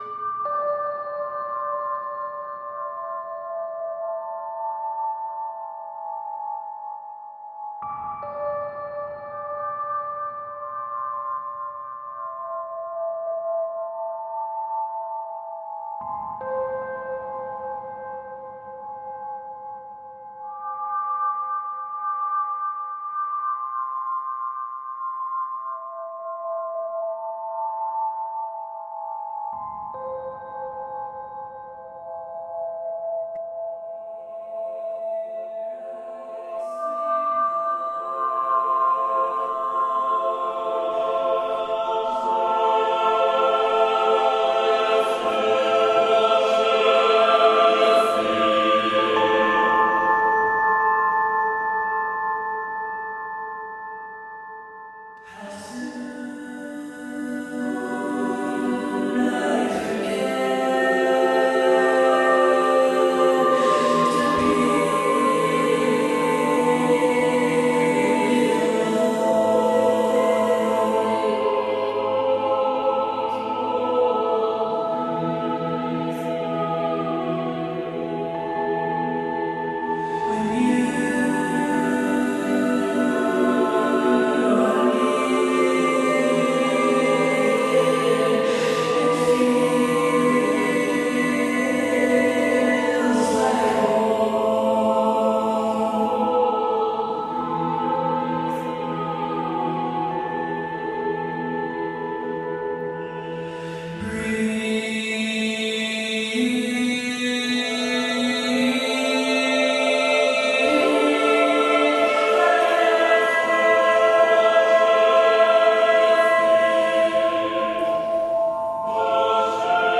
Groove soaked ambient chill.
Alt Rock, Rock, Happy Hour, Remix